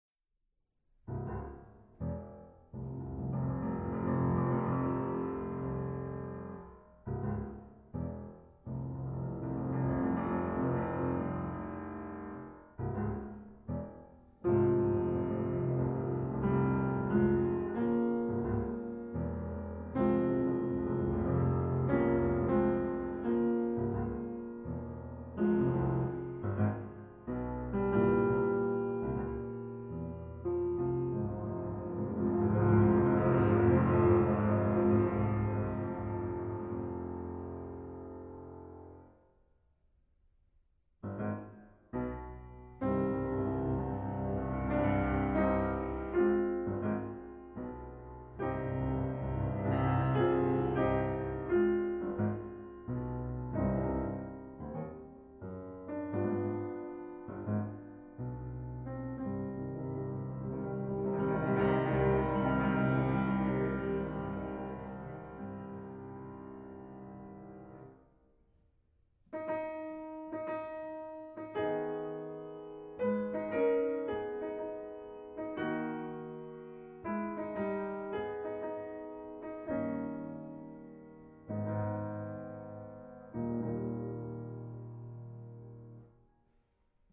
Walzer, Polkas und Märsche
Transkriptionen für Klavier zu vier Händen